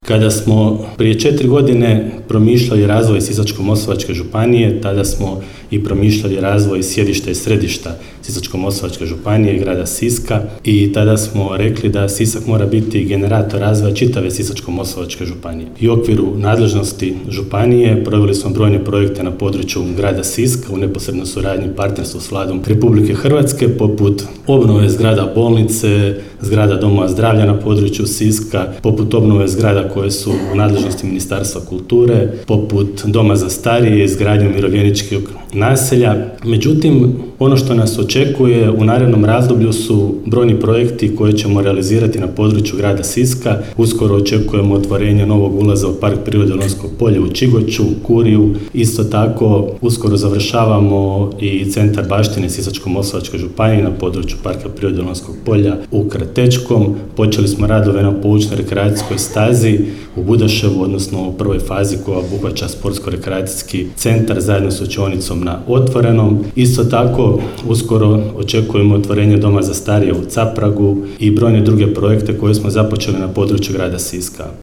Tim povodom u srijedu, 4. lipnja 2025. godine, održana je svečana sjednica Gradskog vijeća Grada Siska.
Župan Sisačko-moslavačke županije Ivan Celjak čestitao je svim Siščankama i Siščanima Dan grada Siska i blagdan sv. Kvirina.